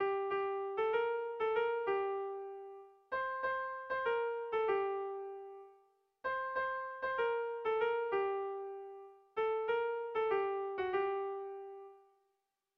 Tragikoa
Lauko txikia (hg) / Bi puntuko txikia (ip)
AB